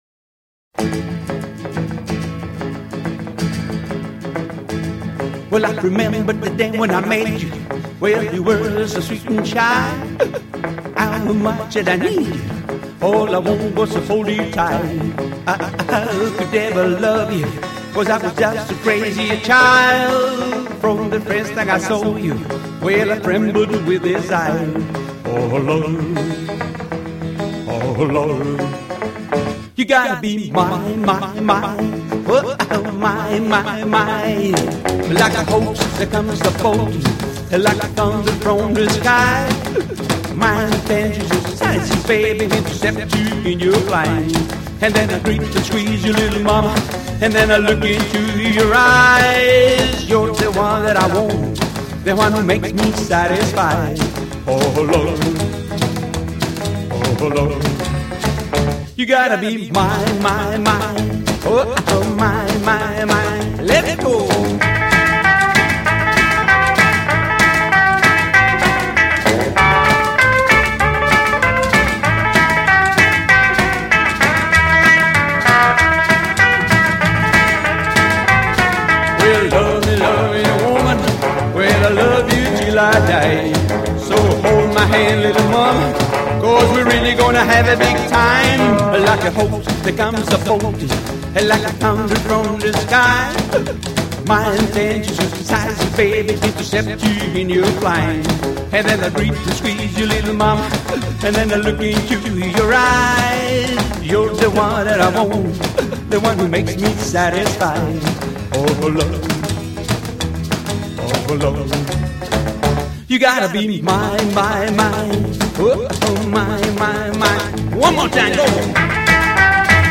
WILD ROCKABILLY